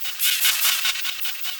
Index of /musicradar/rhythmic-inspiration-samples/150bpm
RI_ArpegiFex_150-01.wav